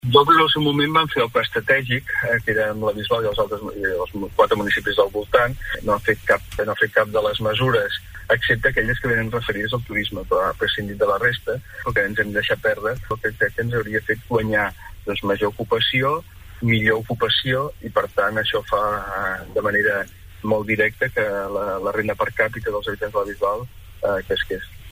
En l’entrevista de Ràdio Capital a Òscar Aparicio la problemàtica de l’aigua a la Bisbal ha estat el tema més comentat pel líder del primer grup d’oposició a l’ajuntament.